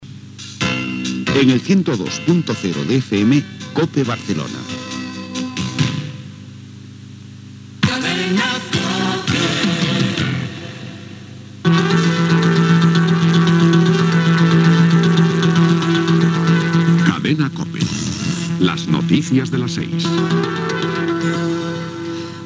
Indicatiu de l'emissora i de la cadena. Careta
FM